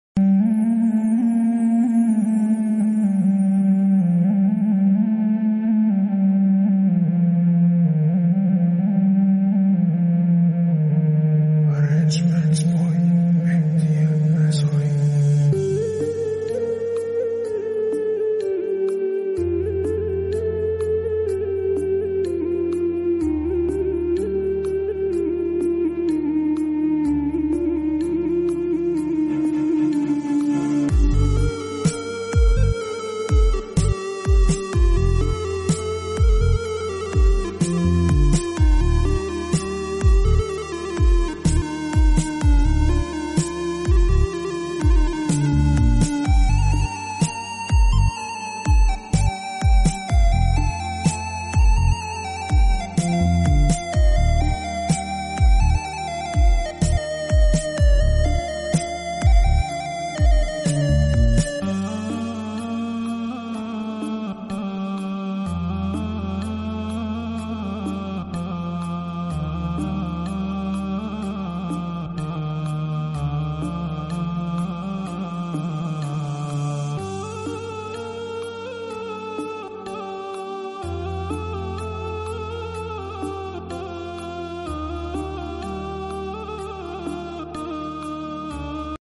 Part 20 l Sad Background sound effects free download
Sad Background Sound